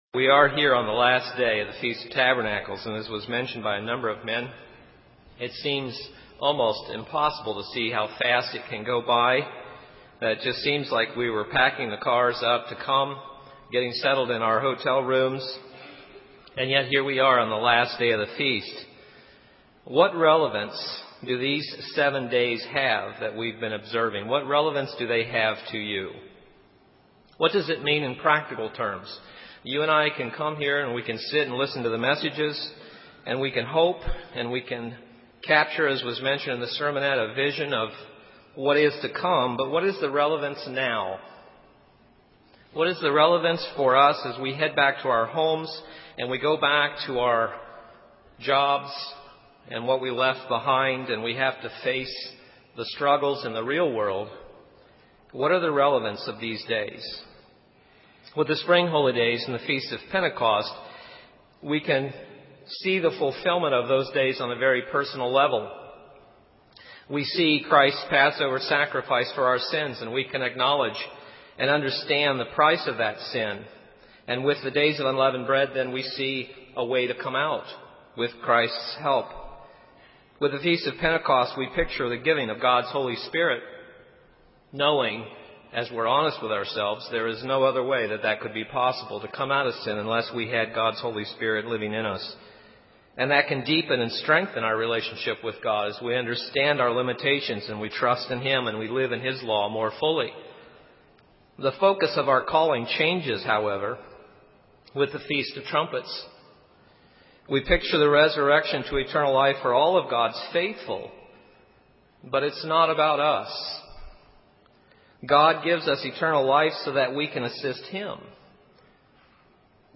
Given in North Canton, OH
(FOT Day 7) UCG Sermon Studying the bible?